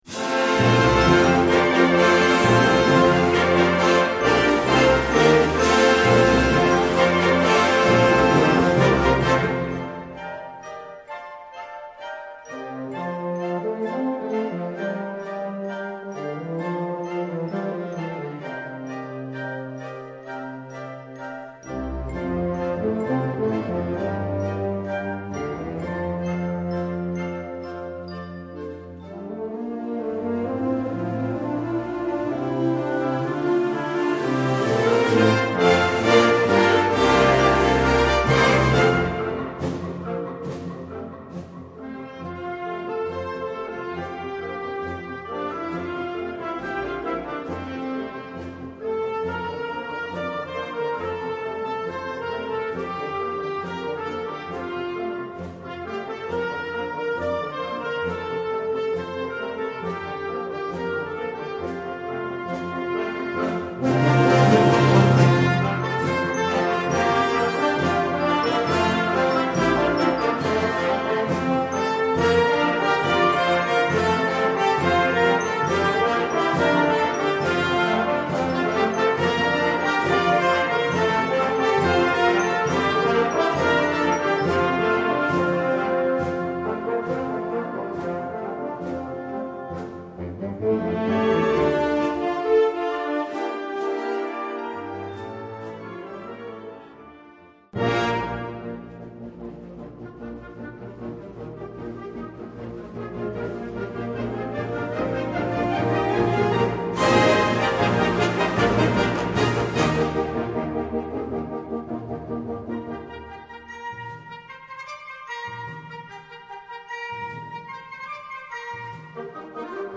Gattung: Suite in 3 Sätzen
Besetzung: Blasorchester